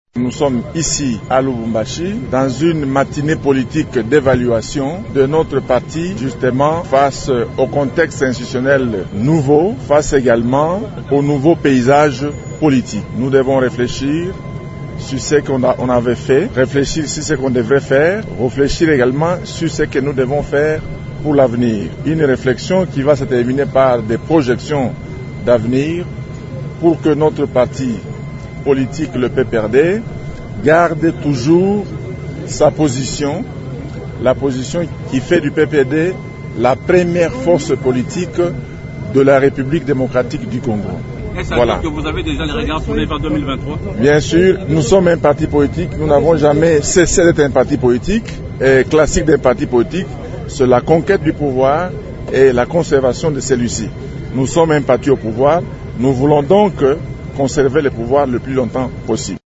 Emmanuel Ramazani Shadary est au micro